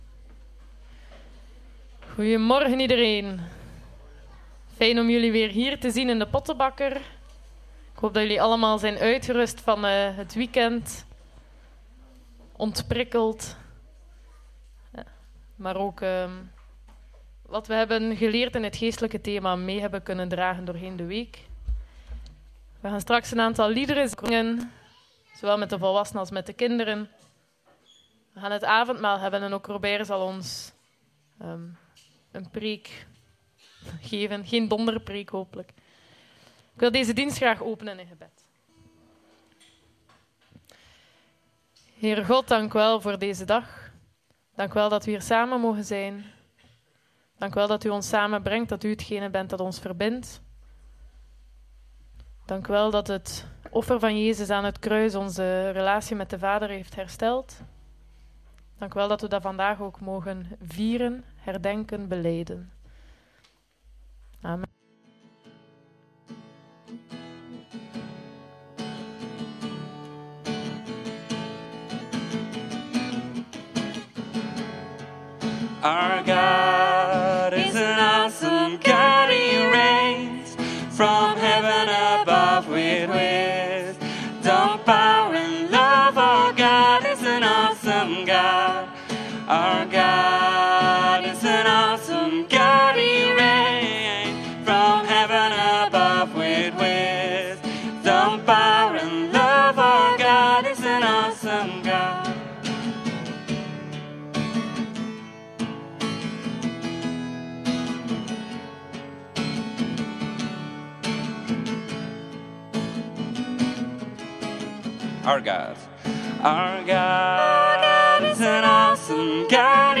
Passage: Numeri 21 : 4—9 en Johannes 3 : 14 Dienstsoort: Zendingsdienst God zet de 1°stap